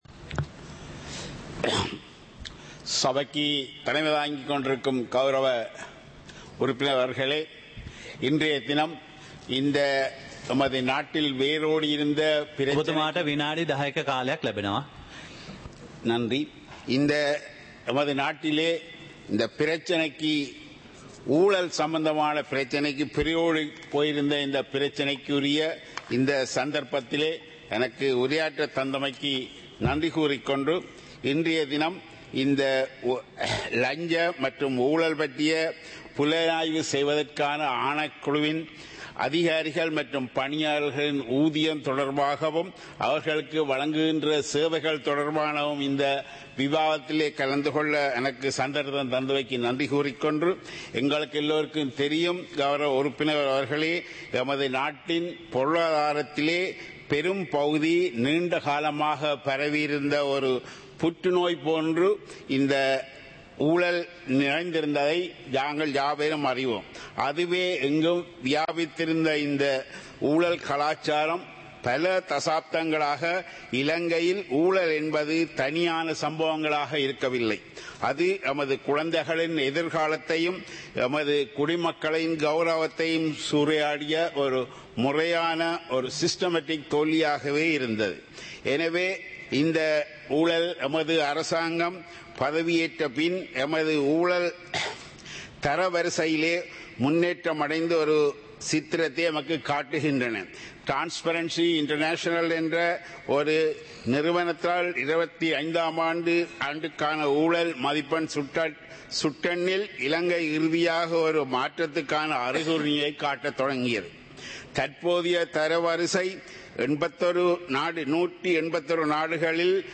Parliament Live - Recorded